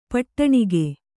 ♪ paṭṭaṇaṇige